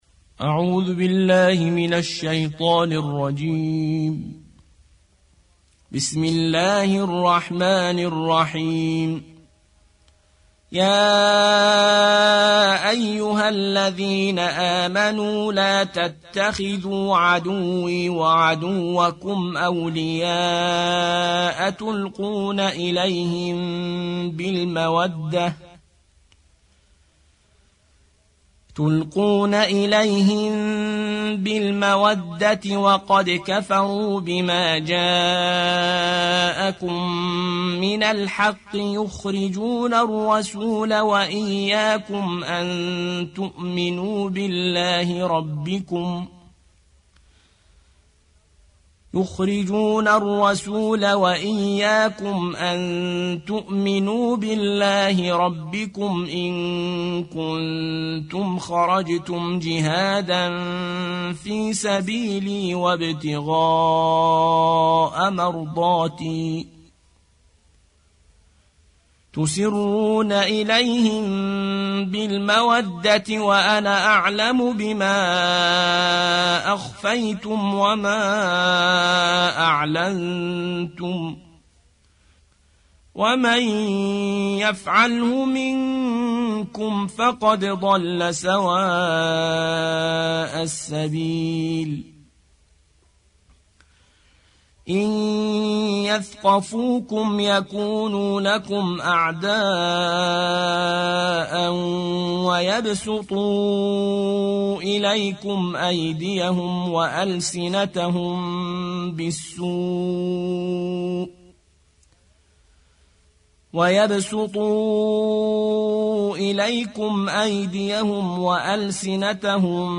60. سورة الممتحنة / القارئ